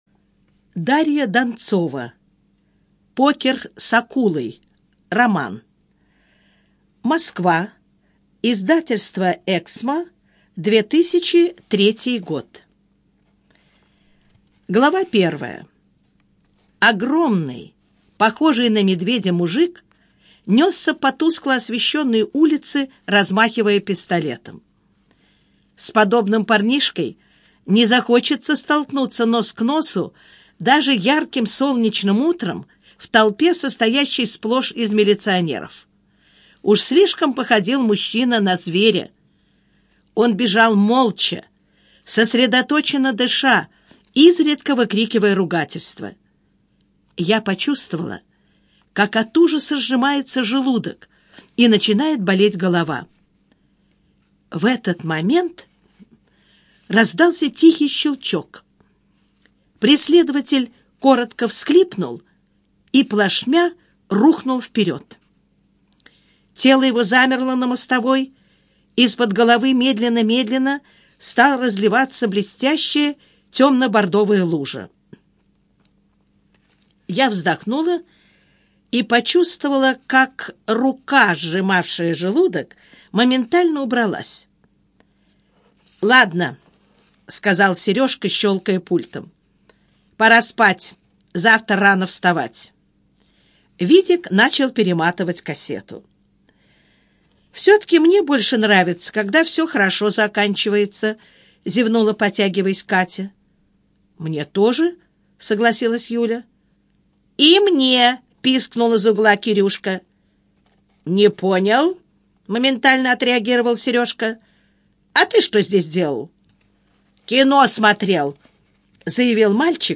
Аудиокнига Покер с акулой - купить, скачать и слушать онлайн | КнигоПоиск